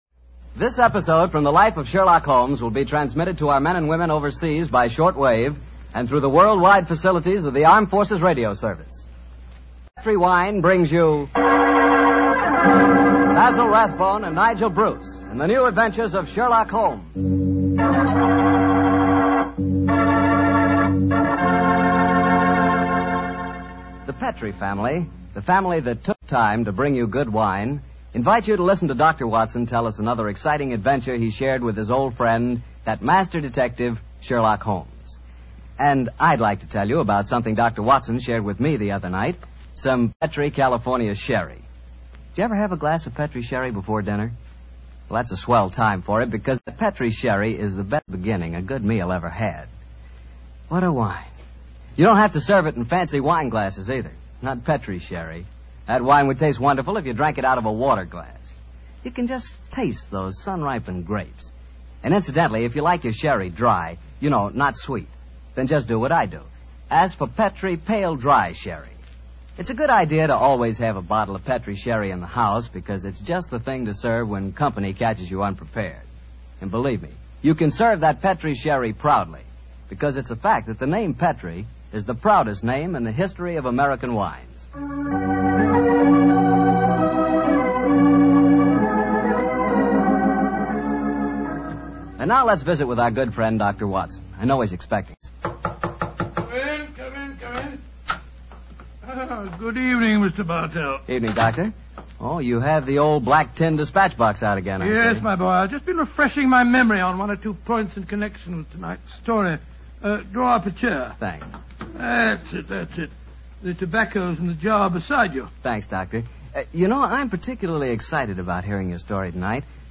Radio Show Drama with Sherlock Holmes - The Great Gandolfo 1945